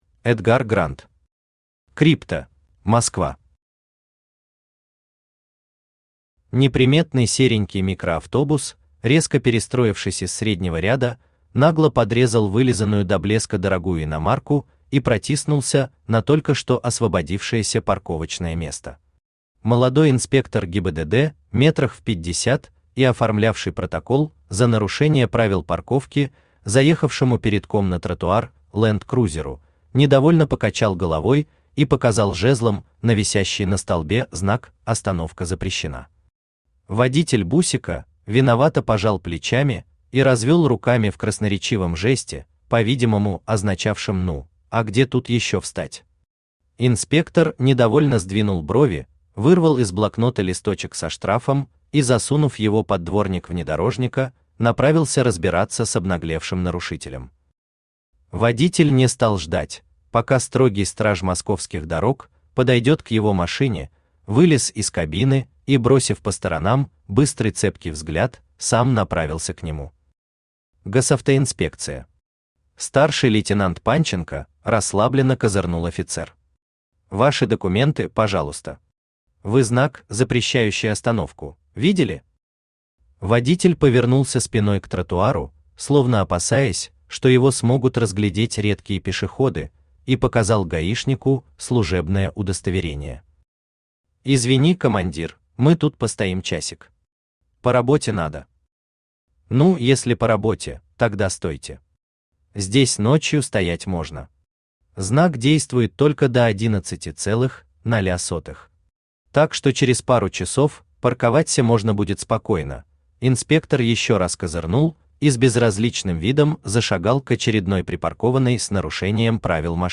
Aудиокнига Крипта Автор Эдгар Грант Читает аудиокнигу Авточтец ЛитРес.